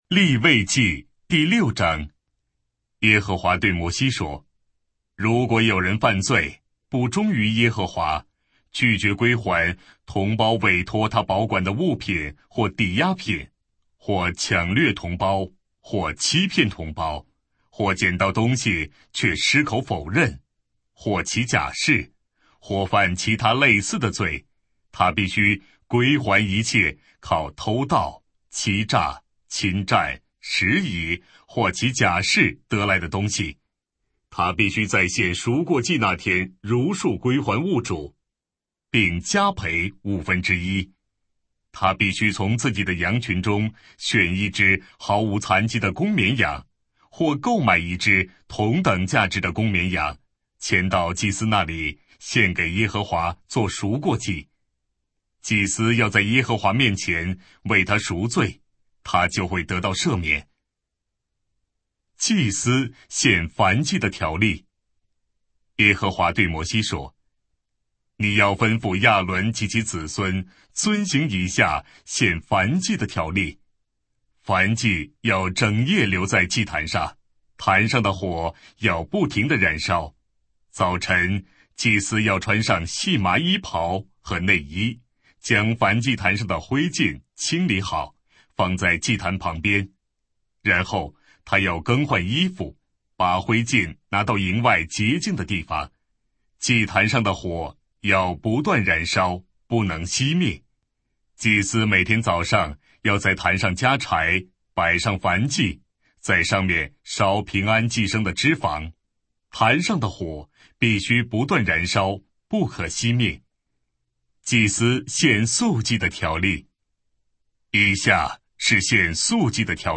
标签： 圣经朗读
当代译本朗读：利未记